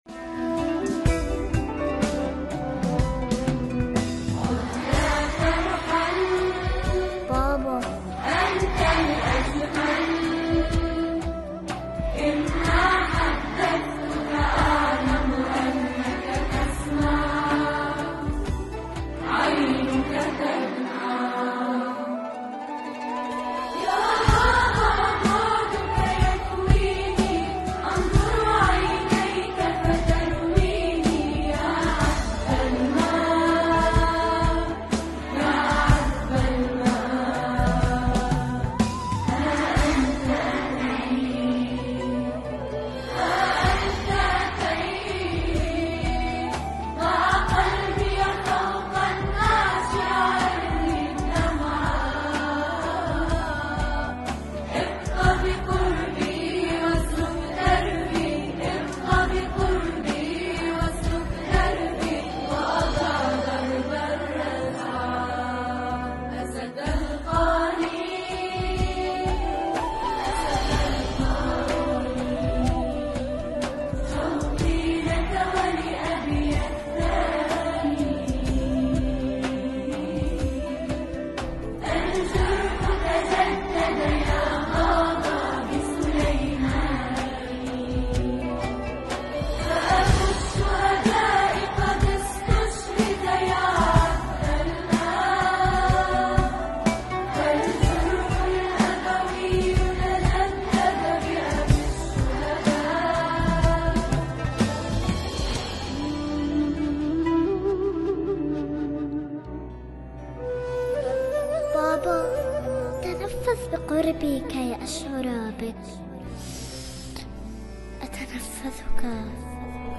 این سرود توسط گروه کر پیشاهنگی امام مهدی عجل الله تعالی فرجه الشریف خوانده شده و گاه صدای زنده سالن هم در آن به گوش می رسد.